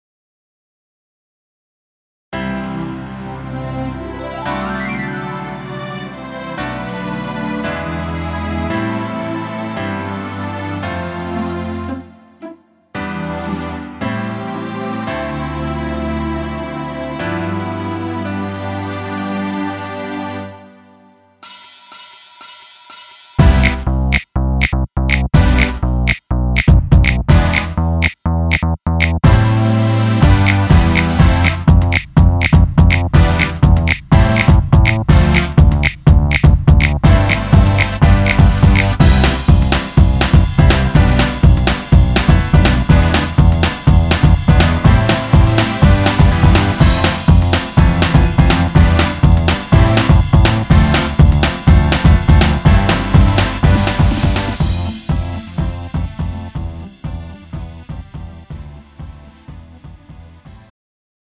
음정 남자키 장르 가요
Pro MR